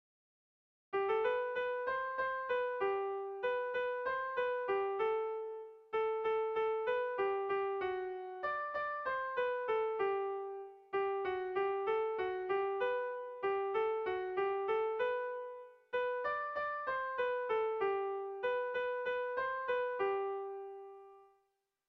Irrizkoa
Zortziko txikia (hg) / Lau puntuko txikia (ip)
ABDE.